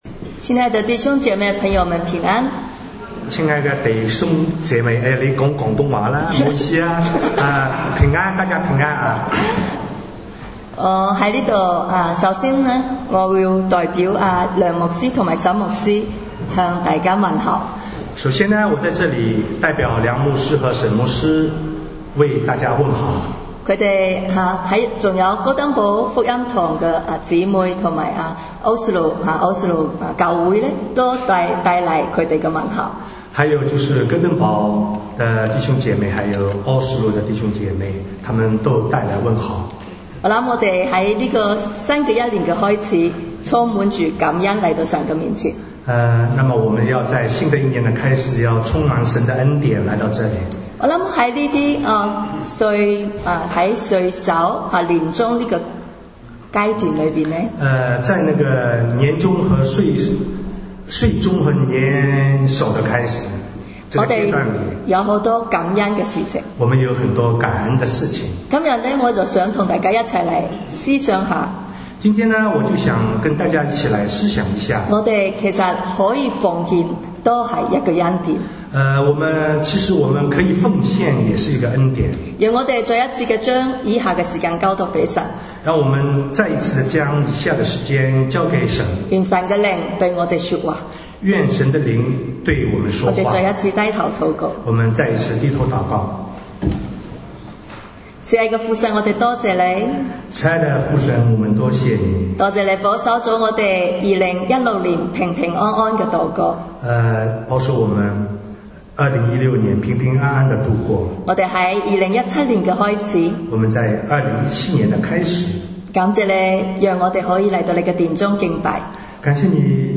讲道